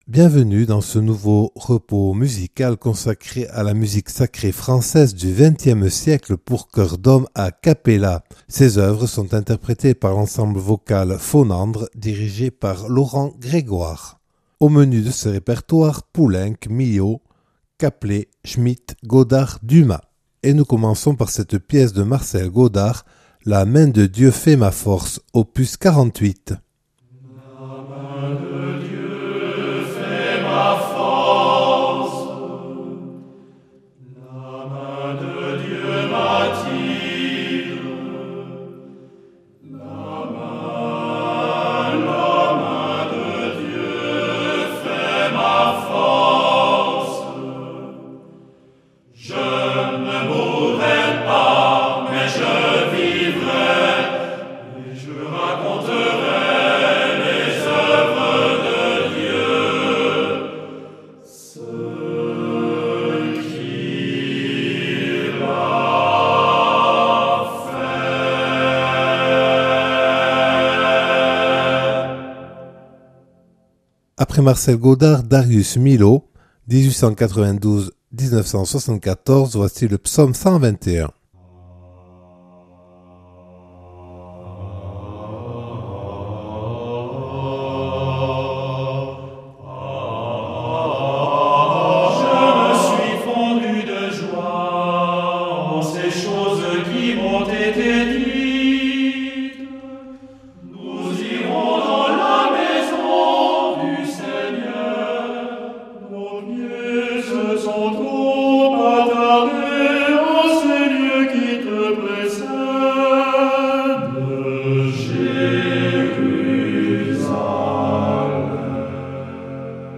Musique Sacree XXe siecle